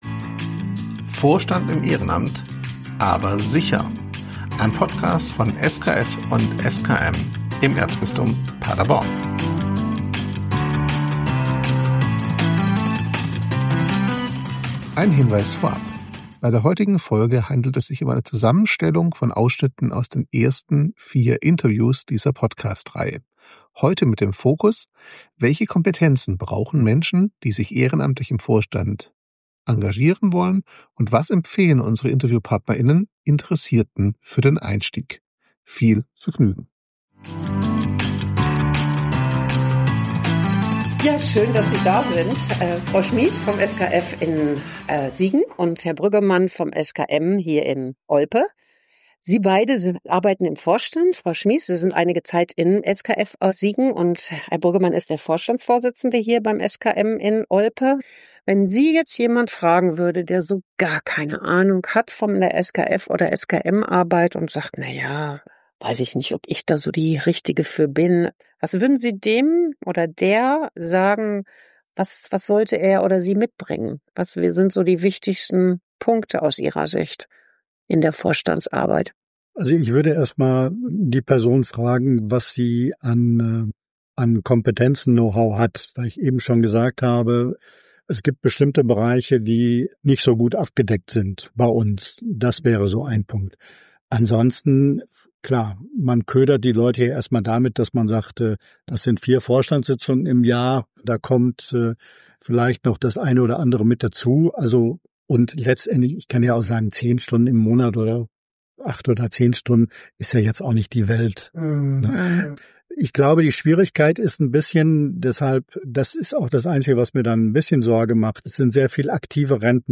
In einer Zusammenstellung von Interviews mit ehrenamtlichen Vorständinnen und Vorständen der Orts- und Diözesan-Ebene von SkF und SKM im Erzbistum Paderborn erhalten wir Einblicke in die Erfahrungen und Empfehlungen der Gesprächspartner*innen. Der Fokus liegt darauf, welche Fähigkeiten und Eigenschaften Menschen mitbringen sollten, die sich für die Vorstandsarbeit interessieren.